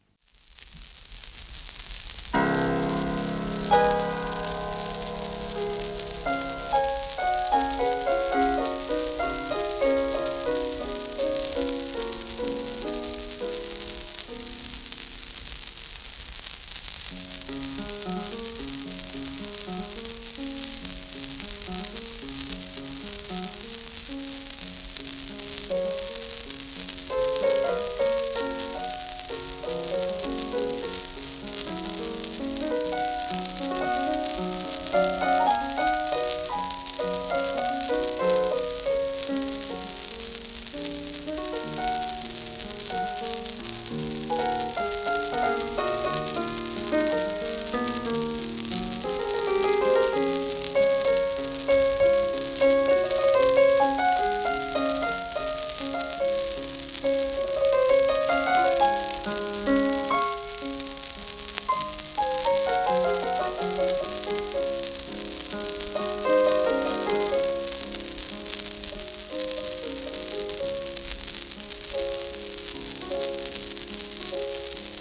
盤質A- *１面外周部薄いスレ、小キズ